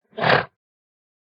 PixelPerfectionCE/assets/minecraft/sounds/mob/horse/donkey/idle2.ogg at mc116